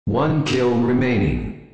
Announcer
OneKillRemaining.wav